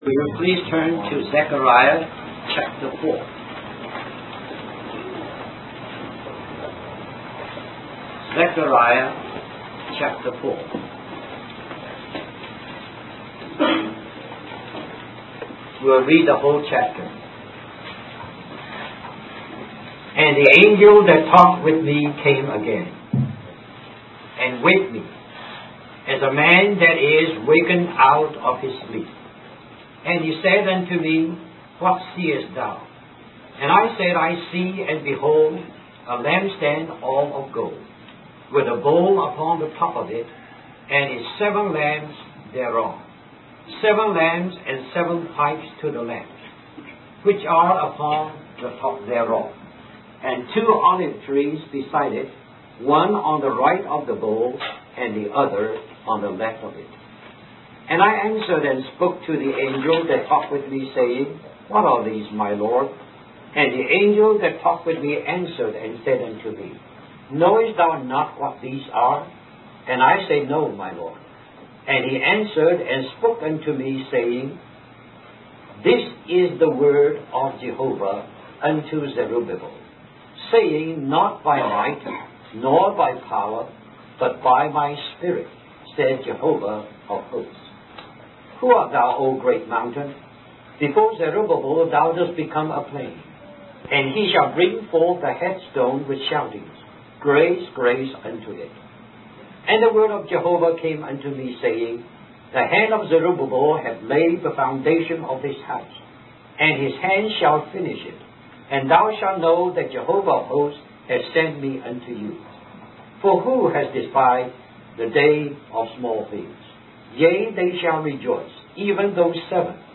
In this sermon on Zechariah chapter 4, the speaker emphasizes the importance of relying on the Holy Spirit rather than human might or power in building the house of God and maintaining the testimony of Jesus.